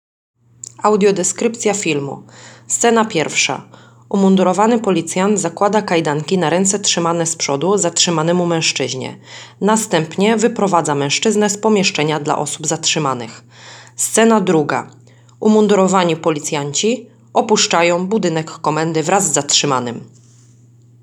Nagranie audio audiodeskrypcja materiału wideo